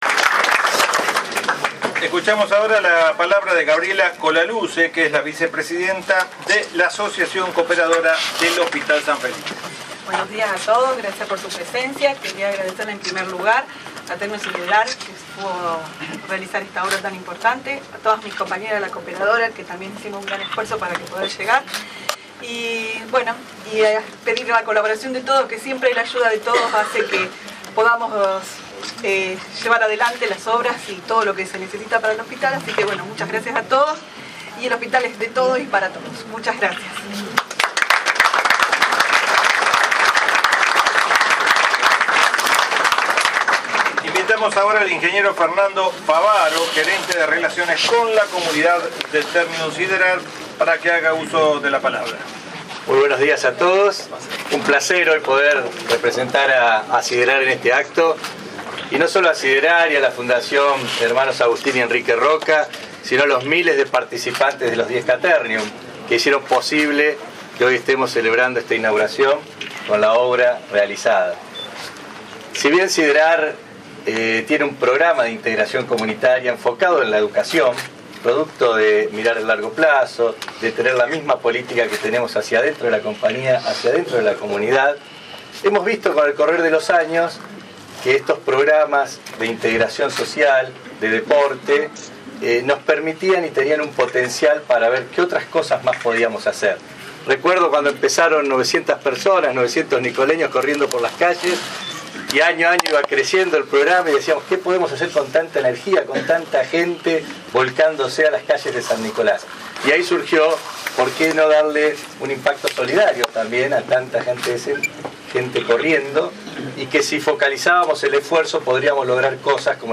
Se realizó un descubrimiento de placa y recorrida de las instalaciones con motivo de la finalización de las obras de puesta en valor del Servicio de Clínica y de Cirugía del Hospital, llevadas a cabo gracias al aporte solidario de la Maratón 10K Ternium realizada en octubre del año pasado.